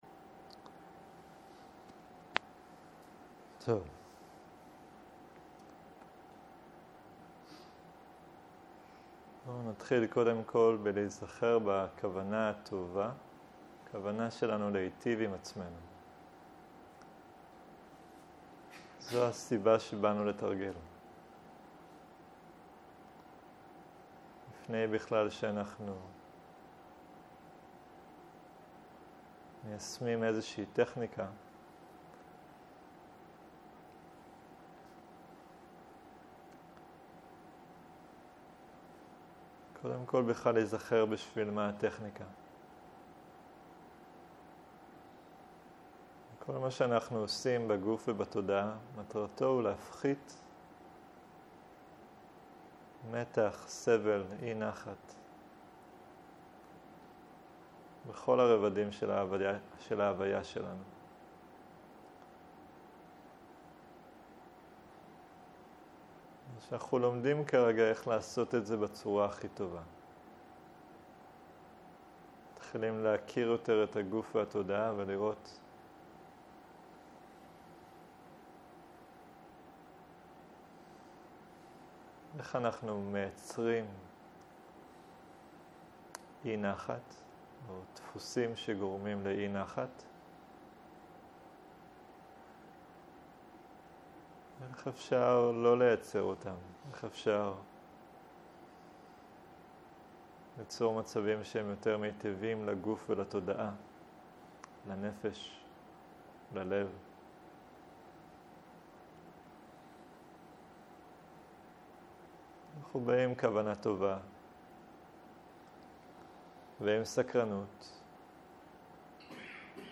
צהריים - מדיטציה מונחית.